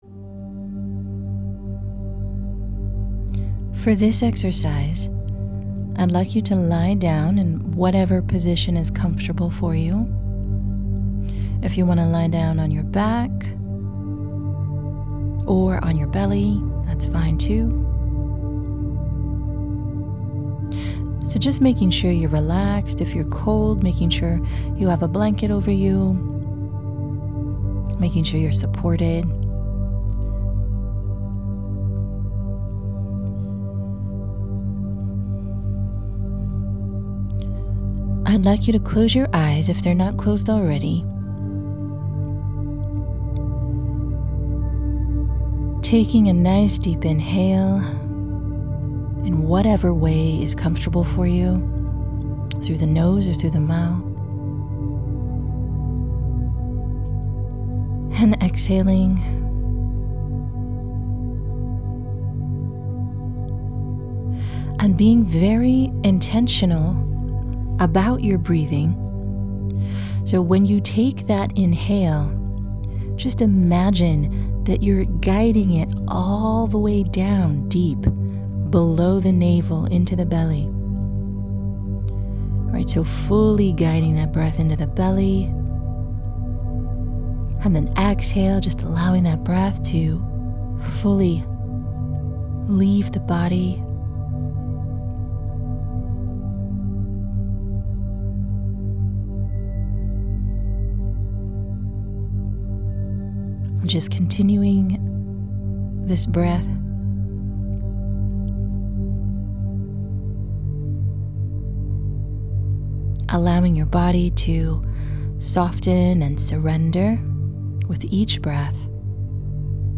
Body Meditation Exercise
Again, headphones are best for this exercise.